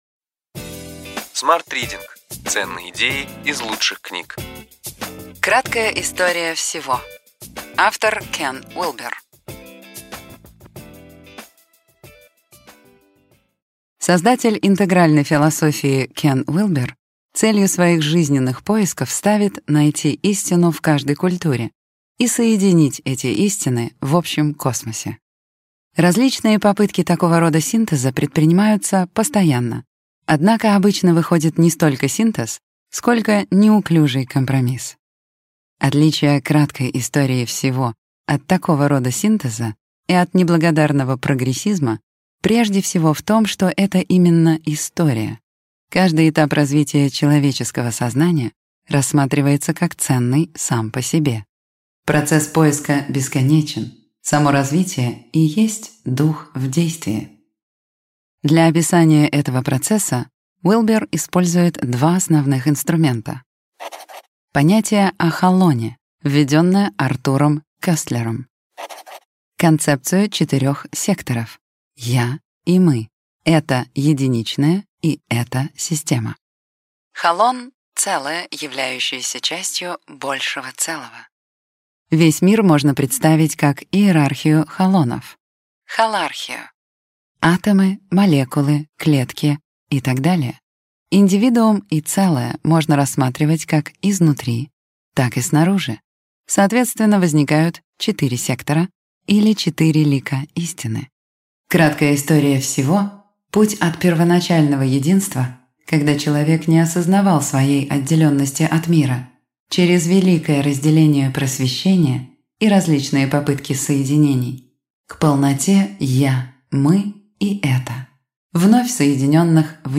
Аудиокнига Ключевые идеи книги: Краткая история всего.